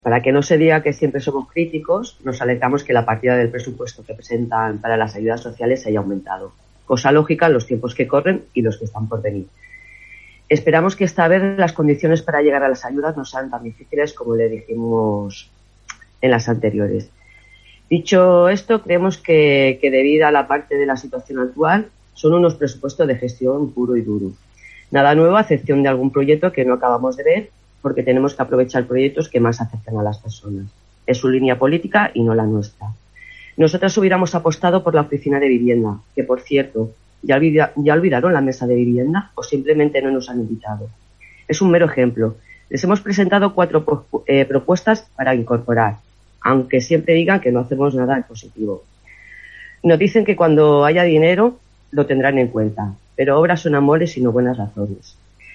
hidalga-ple-press-3.mp3